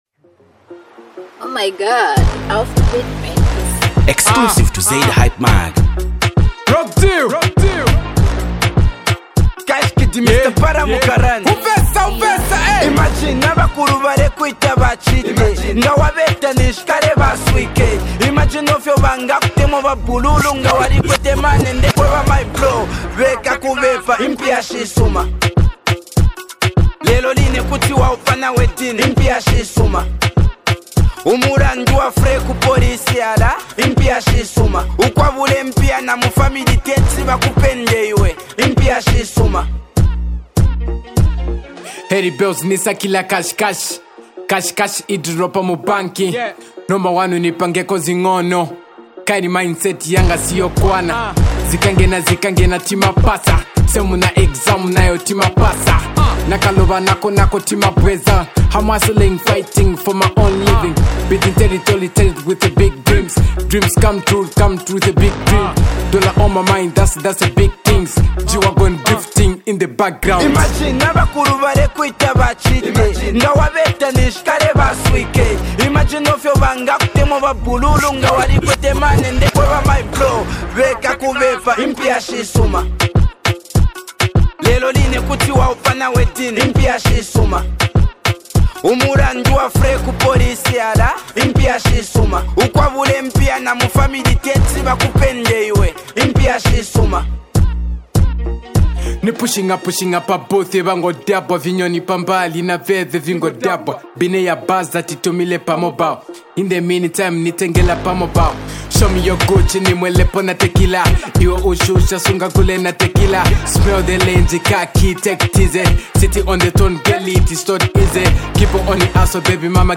HipHop
massive festival banger
The rap fused club song